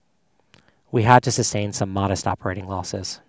noisy